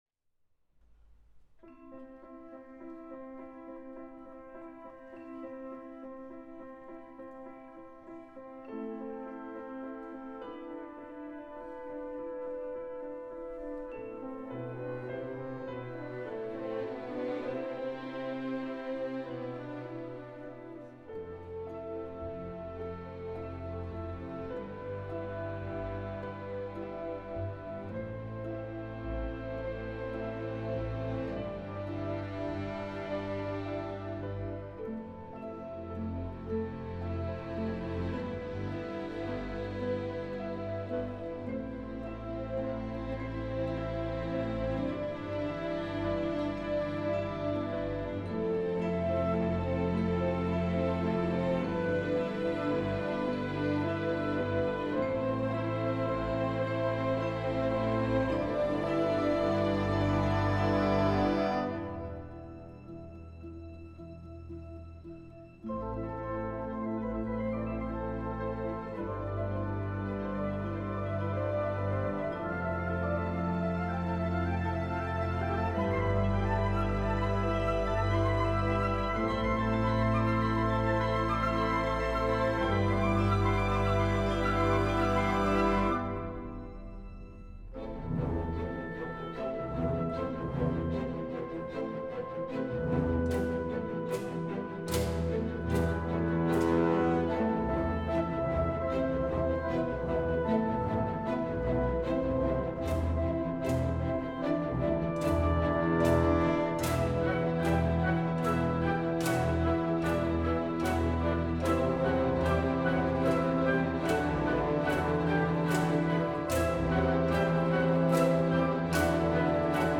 Live-Performance
conductor